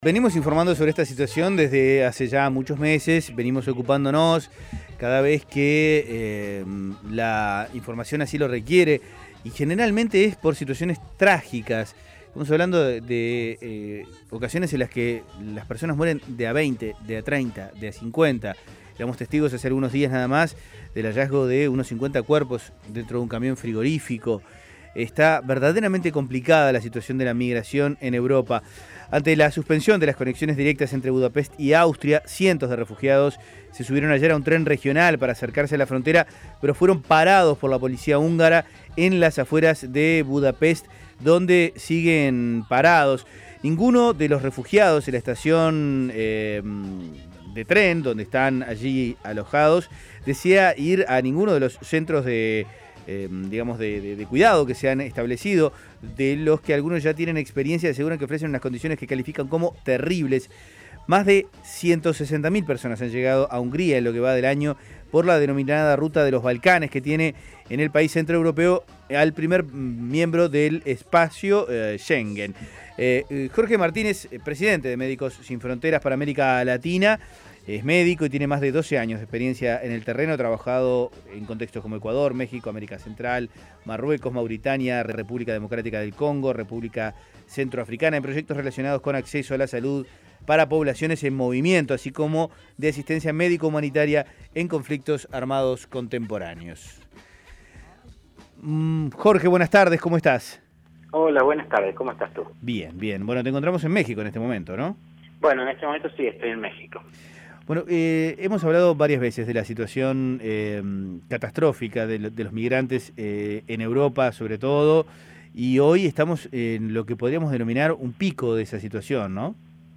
Escuche la entrevista con MSF